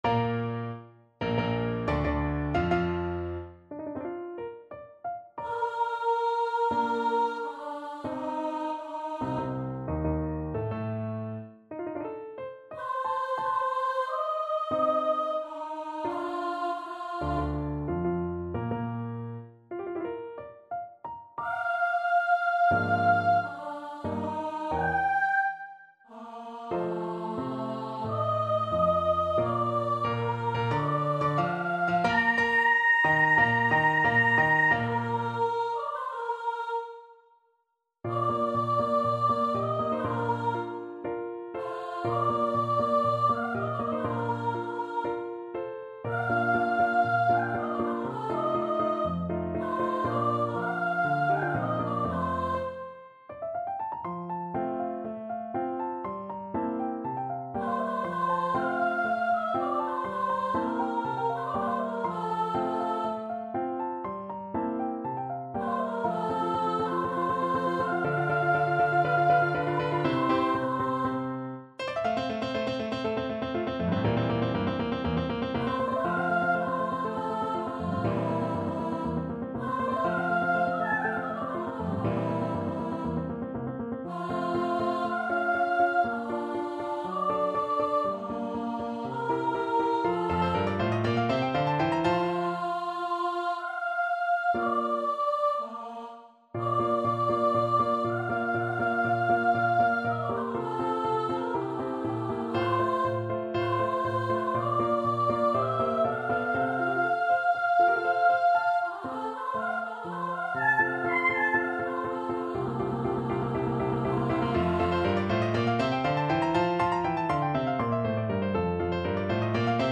4/4 (View more 4/4 Music)
Andante maestoso =90
Classical (View more Classical Soprano Voice Music)